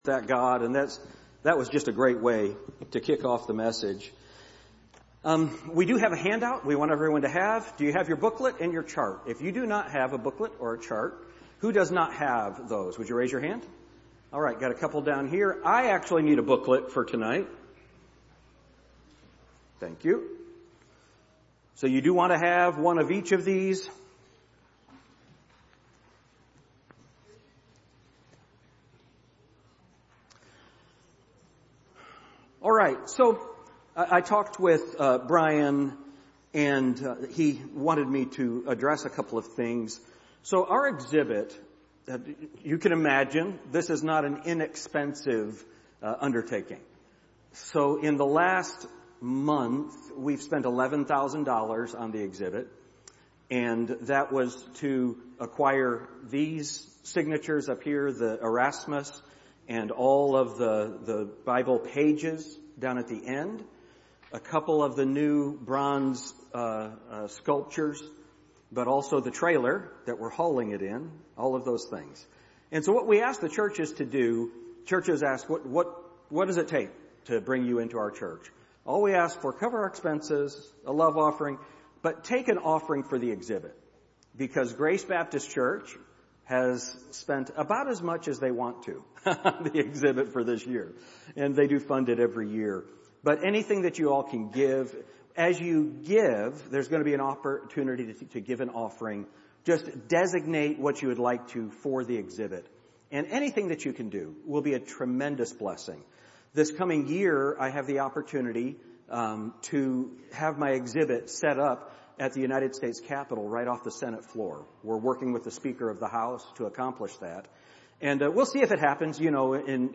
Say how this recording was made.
The Bible Conference